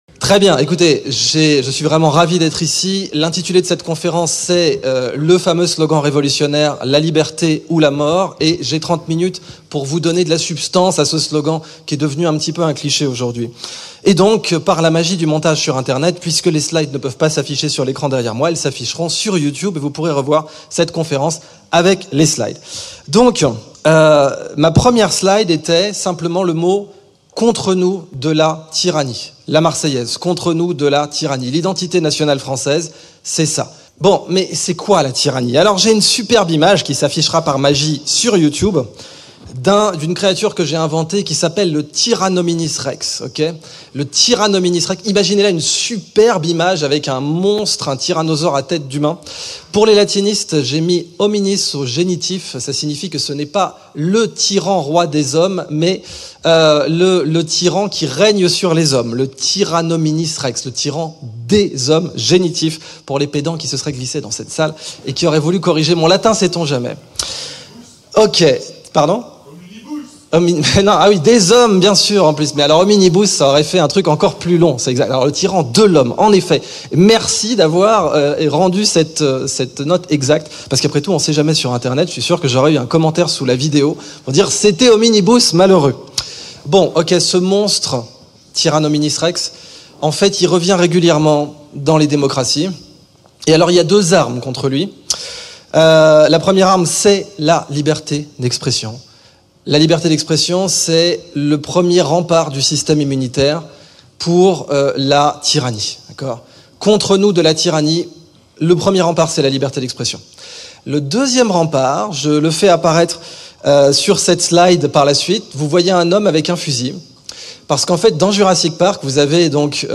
Conference-_La-Liberte-ou-la-Mort_-1.mp3